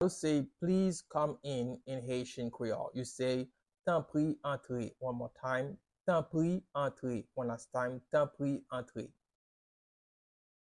Listen to and watch “Tanpri, antre” pronunciation in Haitian Creole by a native Haitian  in the video below:
How-to-say-Please-come-in-in-Haitian-Creole-Tanpri-antre-pronunciation-by-a-Haitian-teacher.mp3